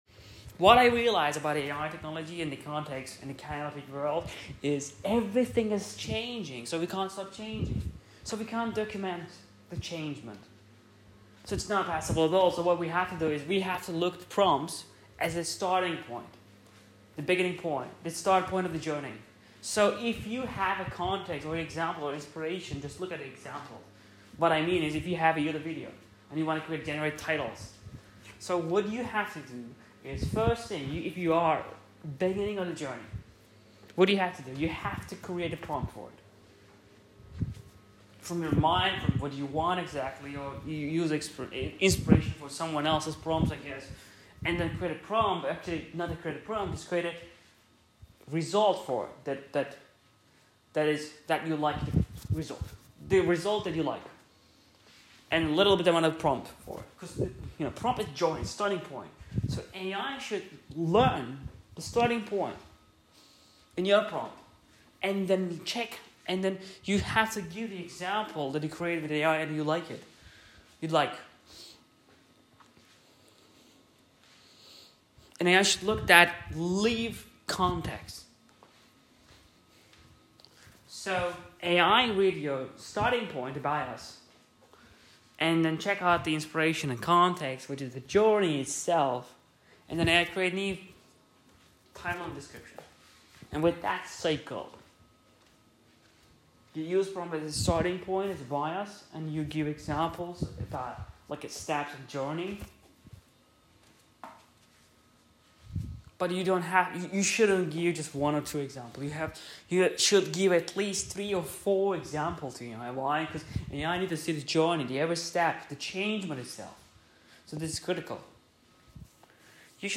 aslindi bunu ai icin kaydetmis ama enerjimi sevdim, isteyen dinlesin (ingilizce)